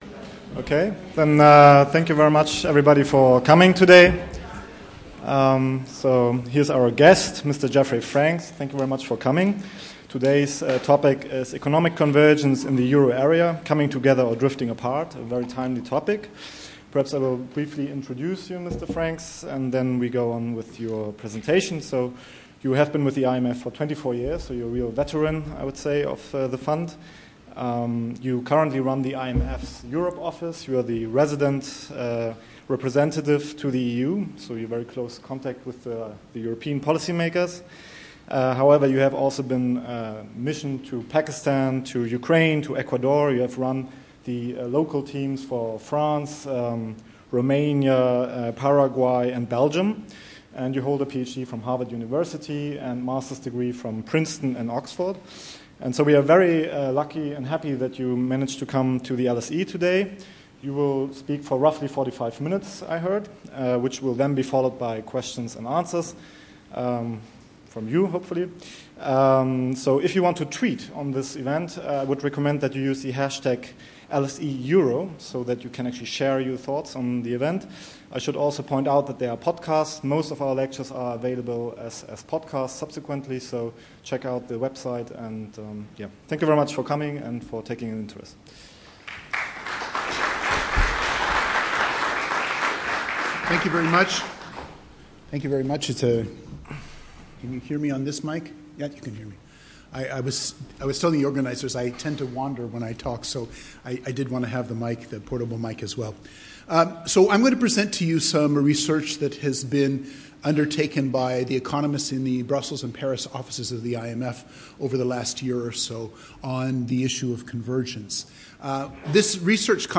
Events Upcoming public lectures and seminars